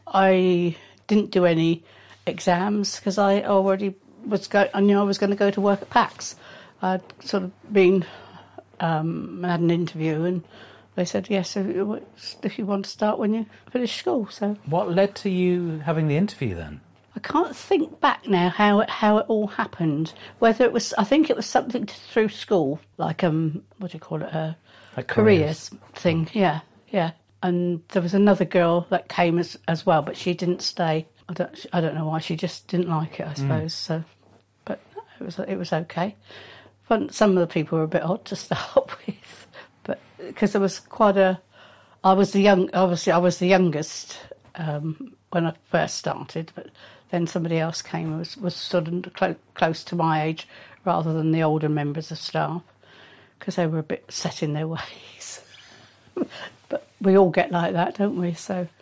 Packs' People Oral History project